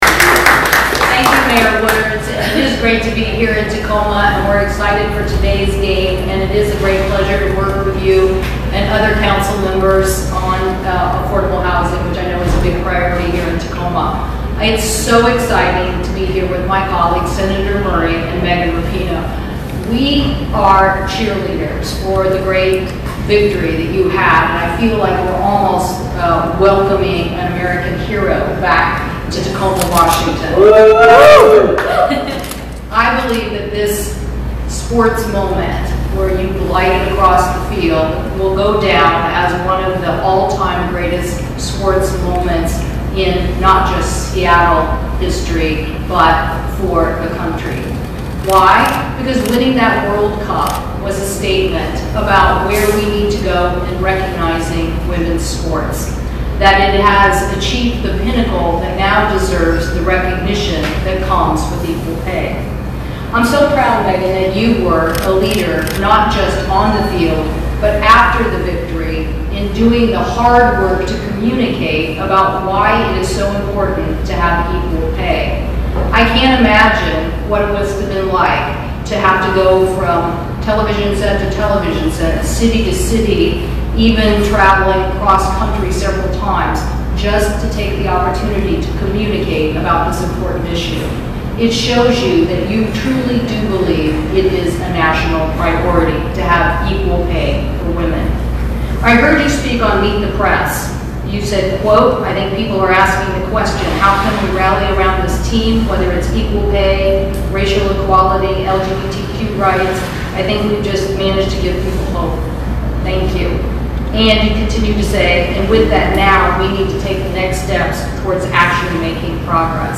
reign-press-conference_audio&download=1